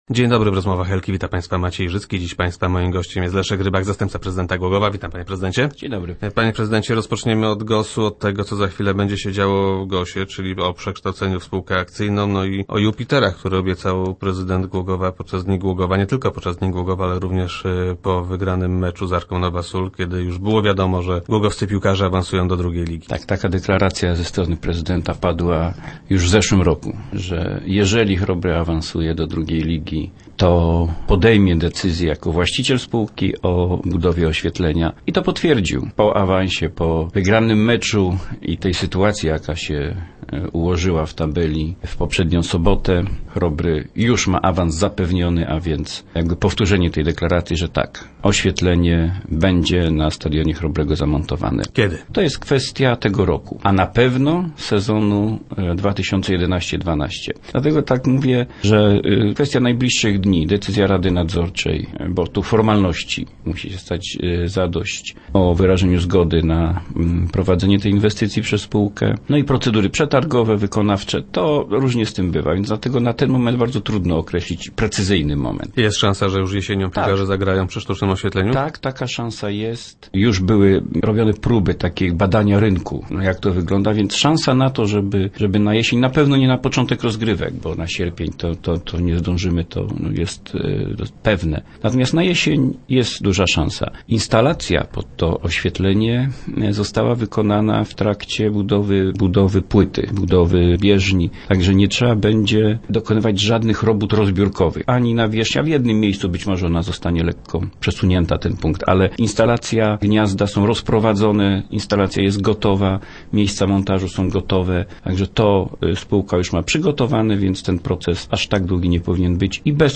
- Bardzo byśmy chcieli tak oświetlić fosę - twierdzi Leszek Rybak, zastępca prezydenta Głogowa, który był gościem Rozmów Elki.